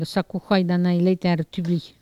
Catégorie Locution